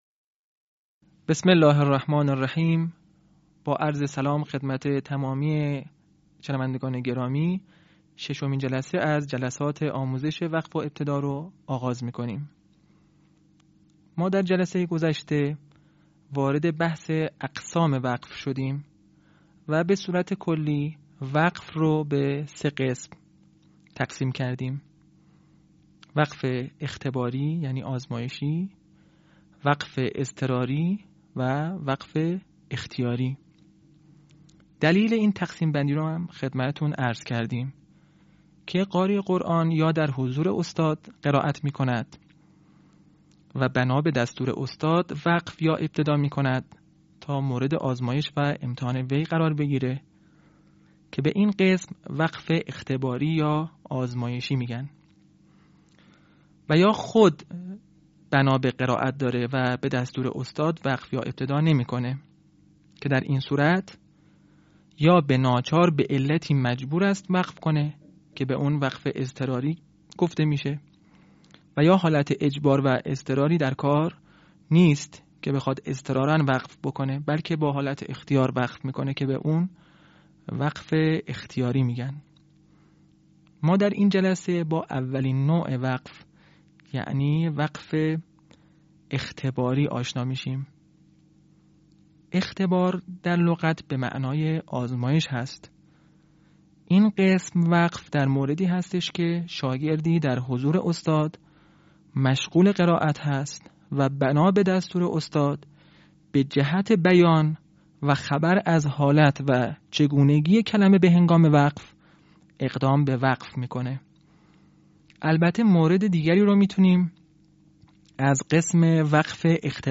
صوت | آموزش «وقف اختباری» در علم وقف و ابتدا